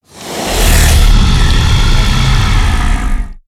sucker_growl_2.ogg